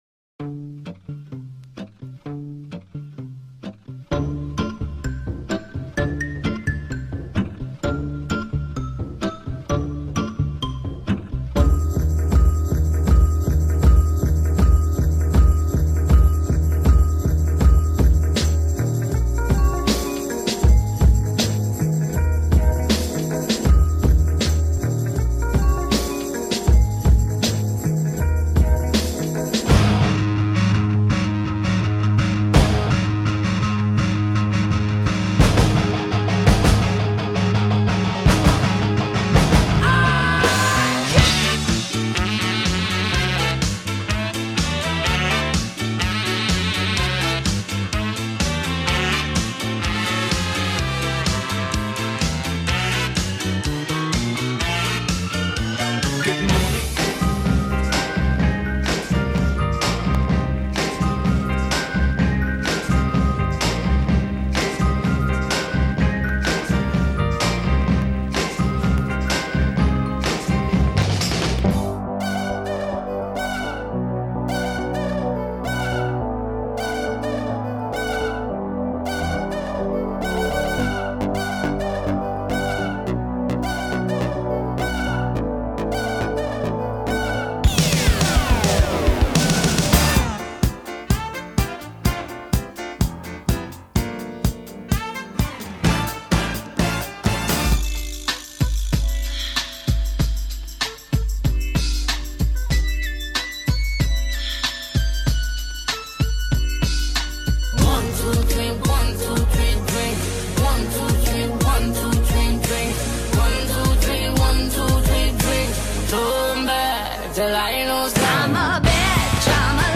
Hit play below and you’ll be treated to snippets from ten songs, all mixed together.